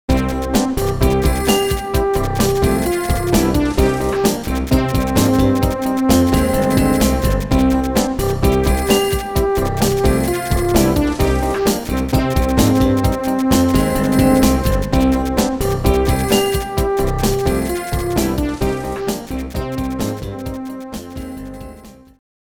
as recorded directly from the Roland MT-32 Sound Module